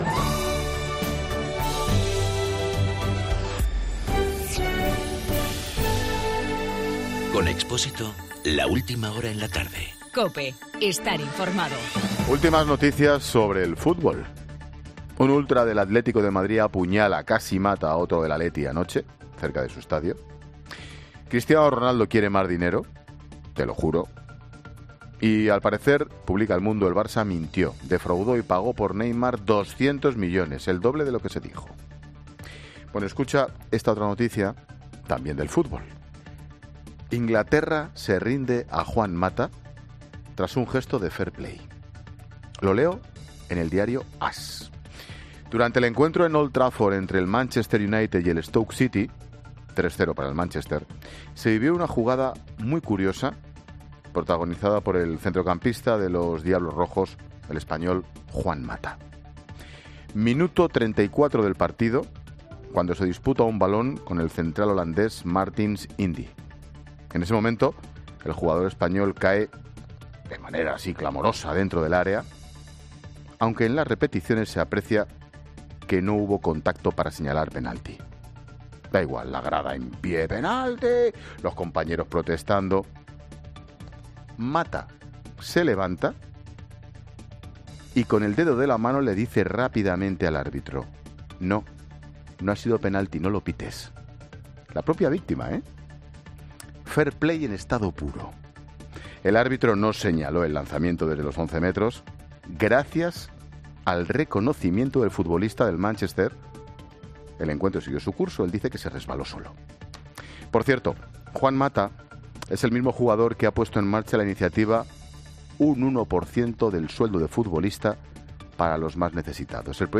AUDIO: El comentario de Ángel Expósito sobre el juego limpio del español Juan Mata en el partido entre el Manchester United y el Stoke City.